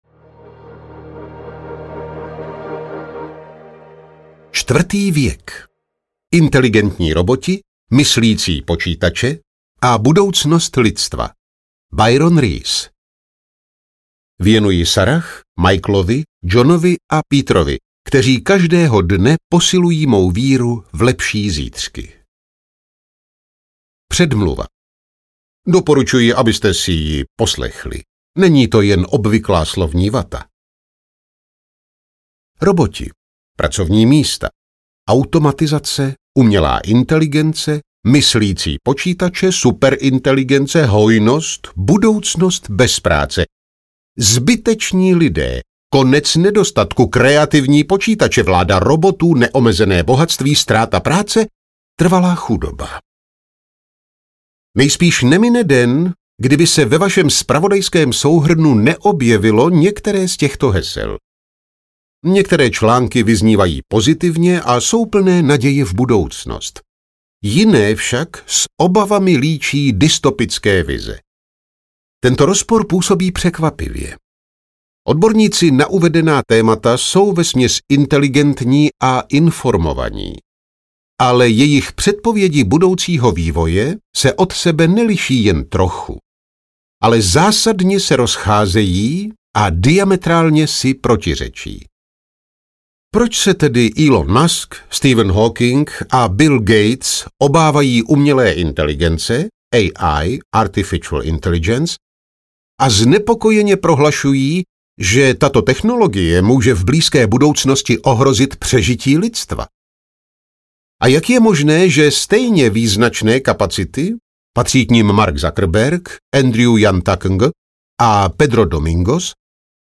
Čtvrtý věk audiokniha
Ukázka z knihy